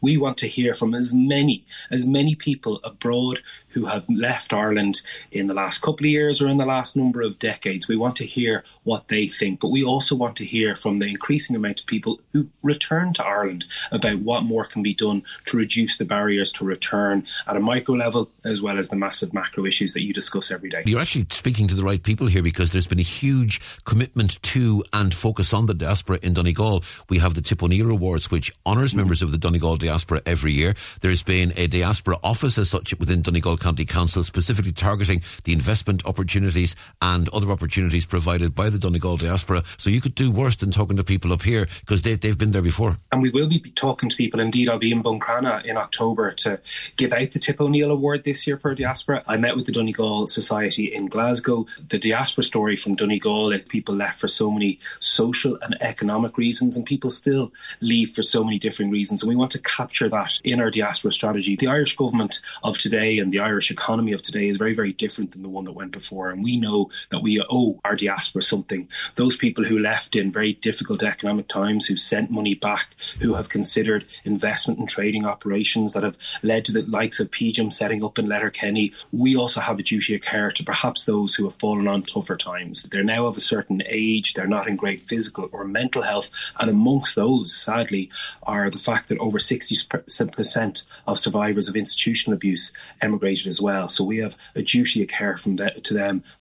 Speaking to Highland Radio earlier today, Junior Minister Neale Richmond said this is the first time such a comprehensive exercise has been undertaken………….